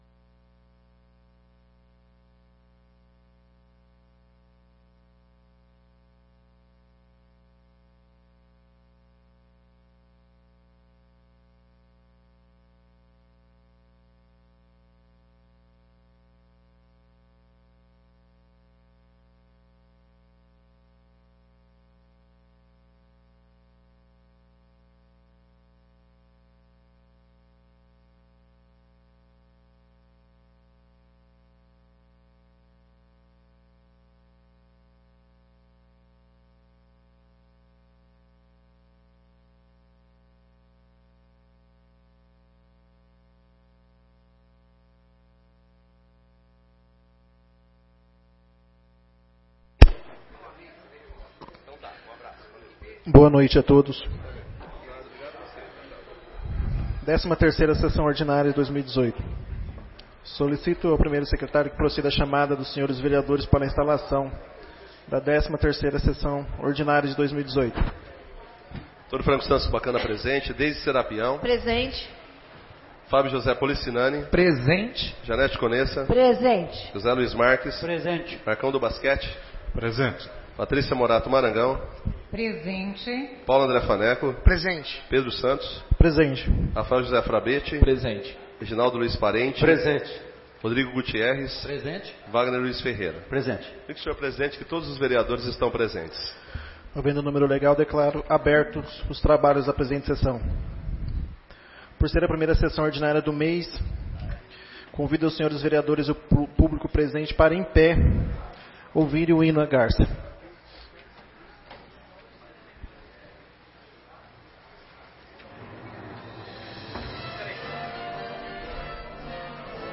13ª Sessão Ordinária de 2018